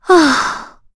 Yuria-Vox_Sigh_kr.wav